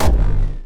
poly_explosion_blackhole2.wav